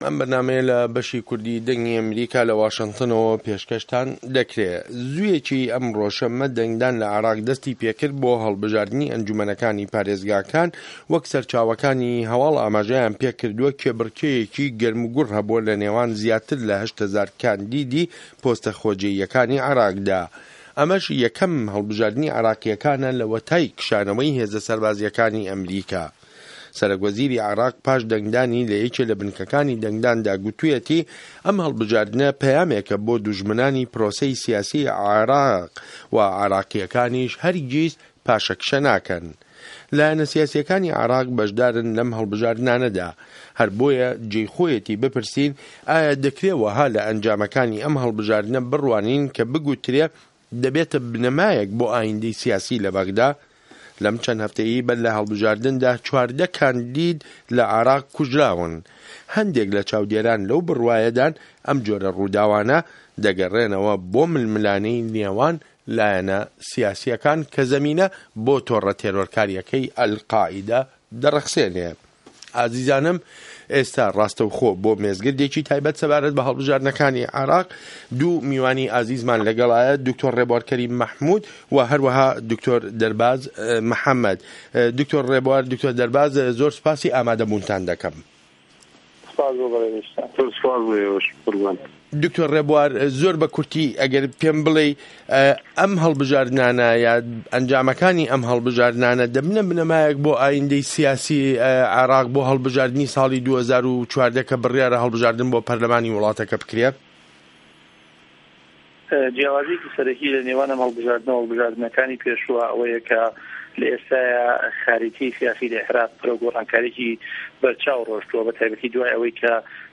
مێزگرد : هه‌ڵبژاردن له‌ عێراق